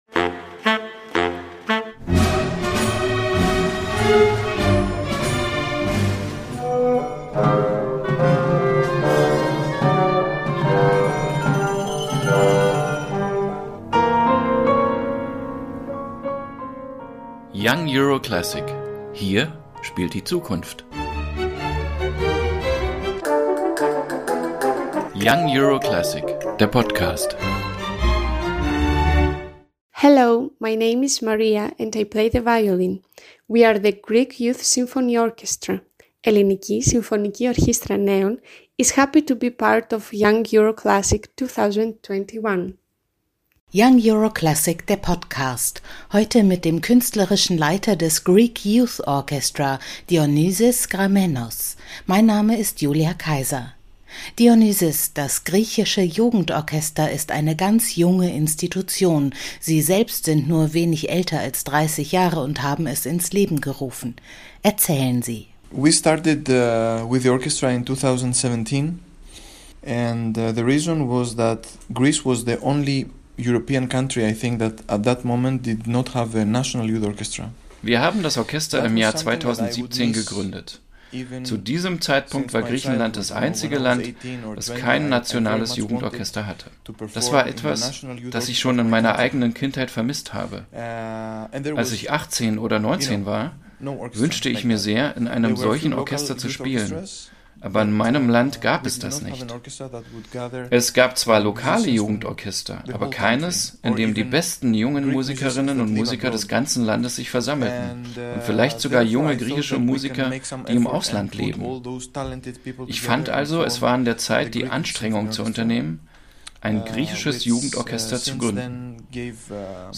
Ein Gespräch über Tradition, Folklore – und einen griechischen Schüler Arnold Schönbergs, der seiner Zeit weit voraus war.